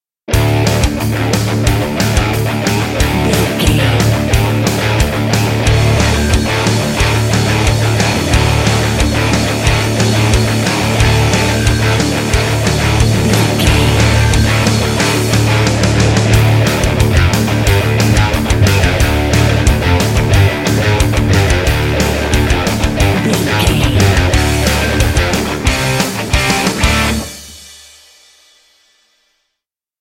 Aeolian/Minor
Fast
aggressive
intense
driving
dark
heavy
bass guitar
electric guitar
drum machine